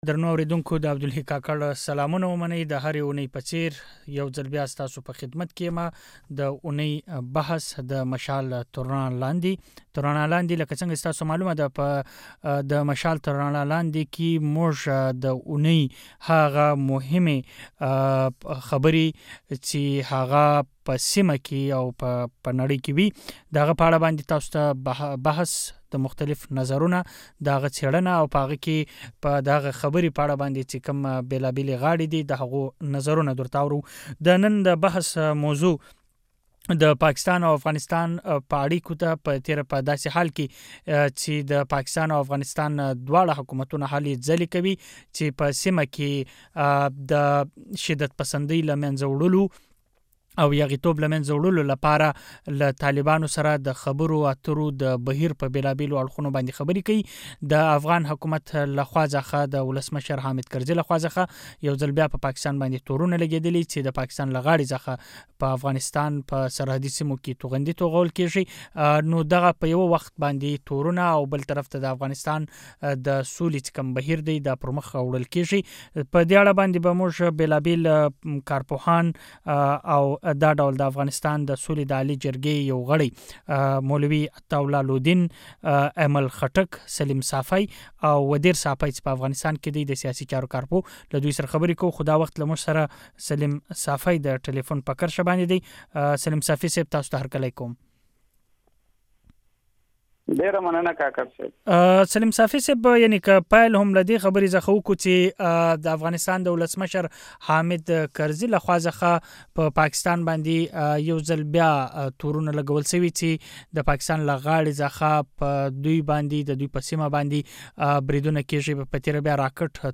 د پاکستان او افغانستان د چارواکو تازه څرګندونو، په سيمه کي له طالبانو سره د جوړ جاړي هڅي او د نورو سياسي پر مختګونو په اړه د مشال تر رڼا لاندي بحث شوی دی.